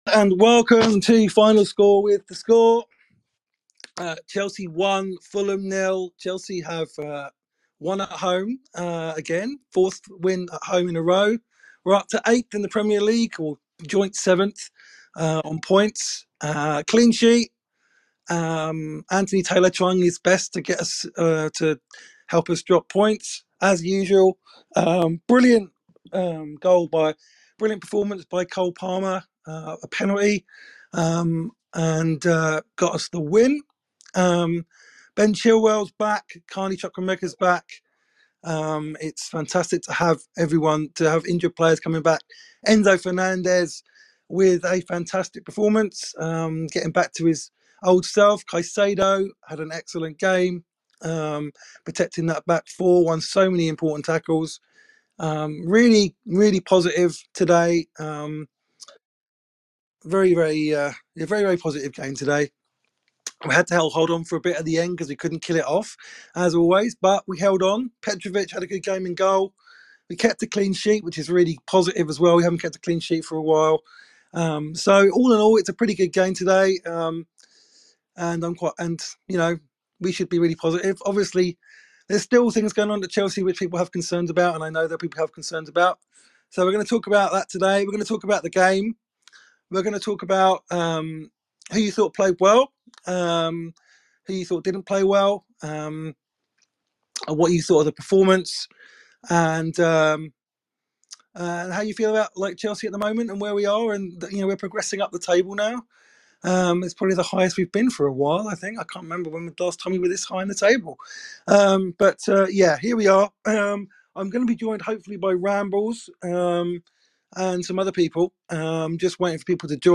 The instant post-game show where we take your calls and opinions, as well as getting the news straight from the ground.